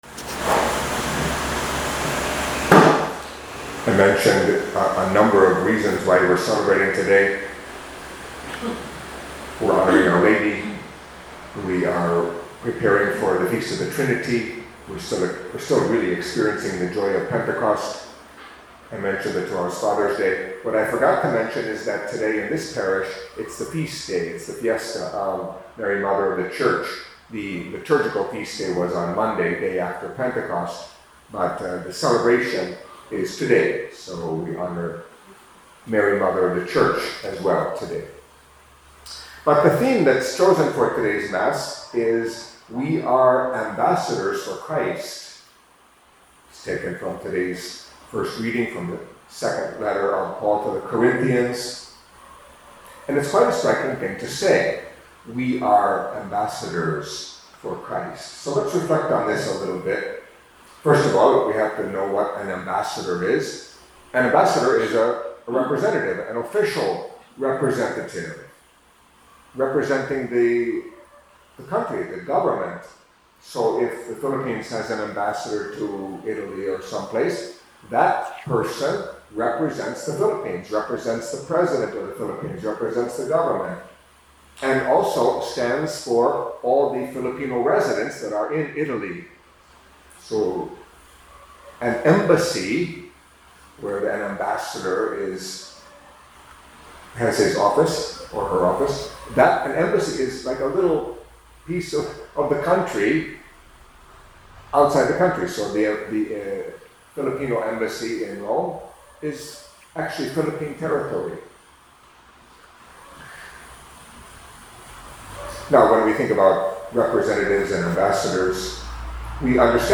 Catholic Mass homily for Saturday of the Tenth Week in Ordinary Time